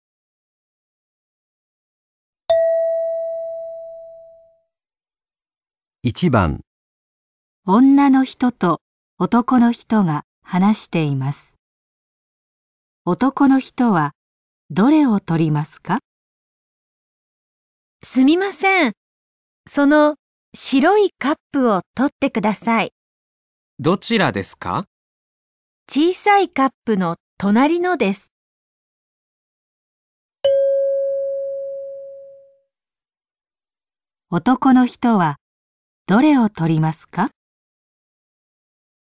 女人和男人在说话。